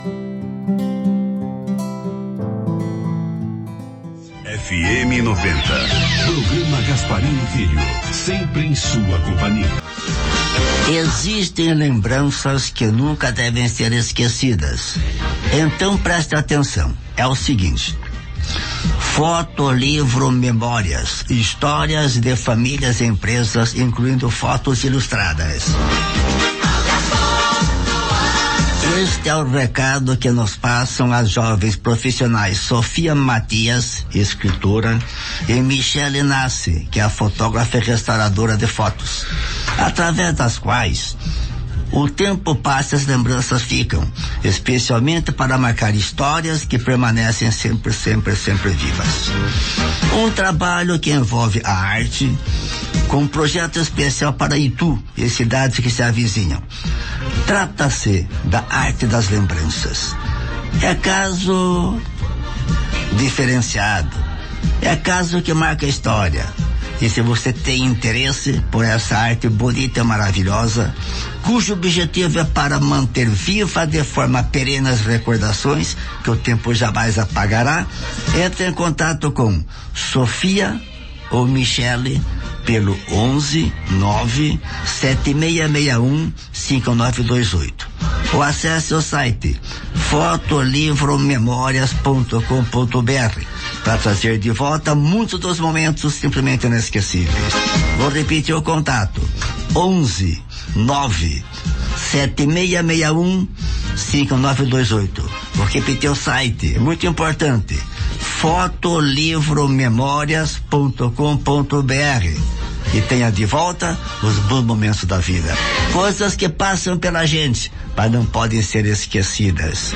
Spot para a Radio FM90